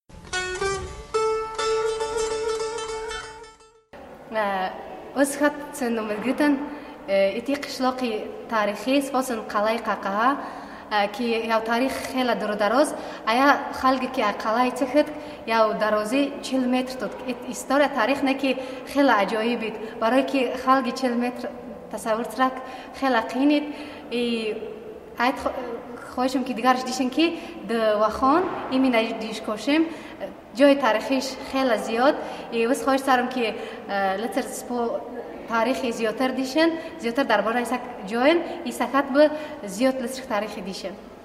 I look for a more “rounded”, “aw” sound to vowels if I’m guessing that someone is speaking Persian, and those sounded more “pure” and “clipped”.